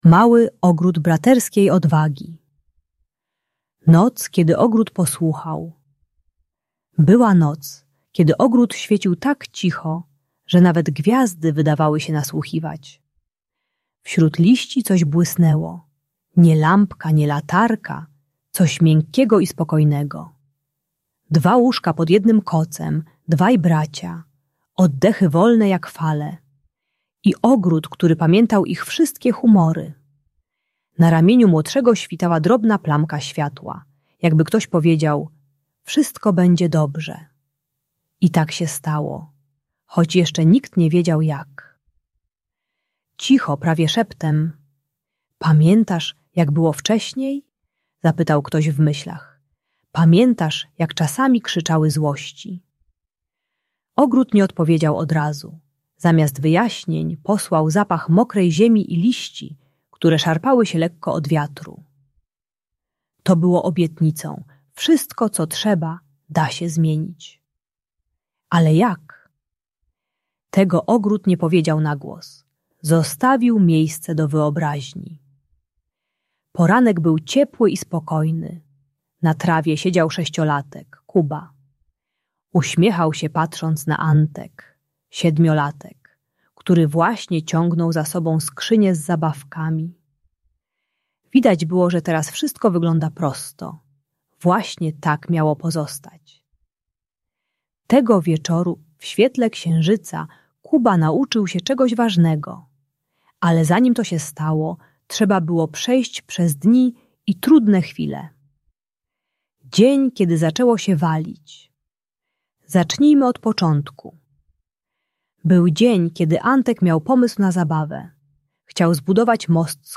Mały Ogród Braterskiej Odwagi - story o braterstwie - Bunt i wybuchy złości | Audiobajka